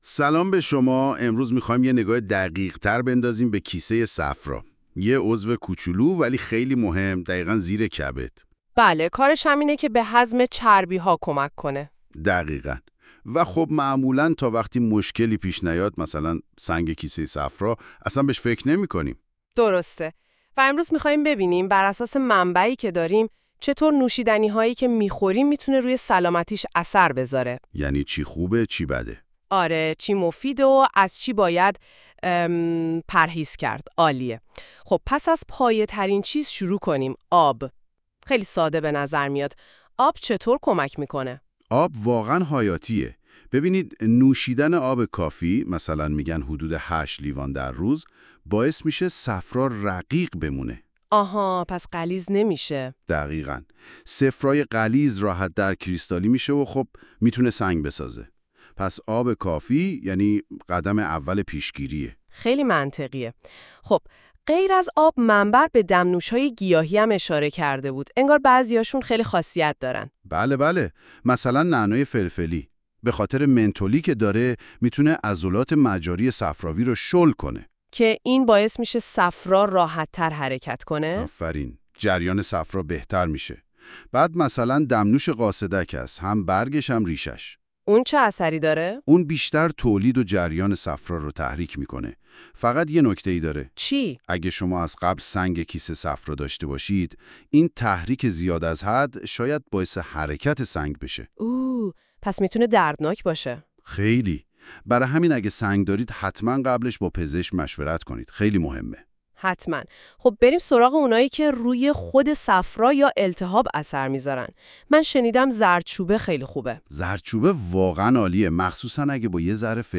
🎧 خلاصه صوتی بهترین نوشیدنی ها برای کیسه صفرا
این خلاصه صوتی به صورت پادکست و توسط هوش مصنوعی تولید شده است.